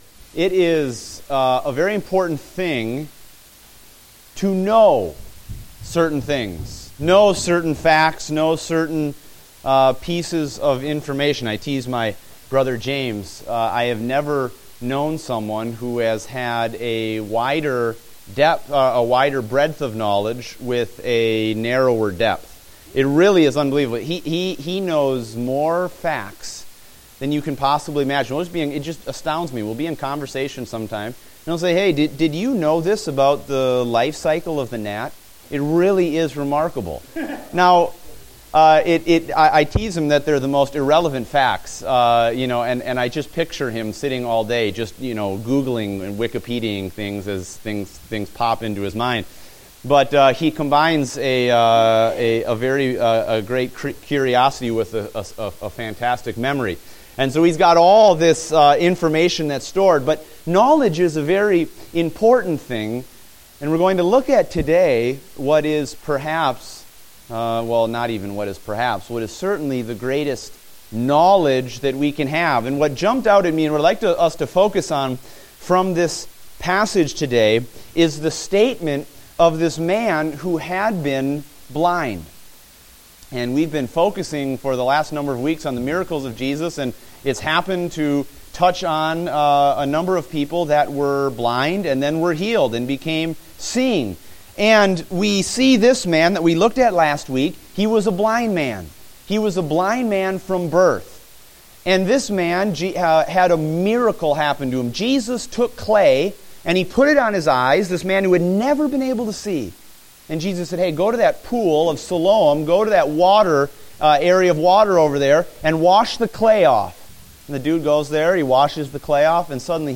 Date: January 24, 2016 (Adult Sunday School)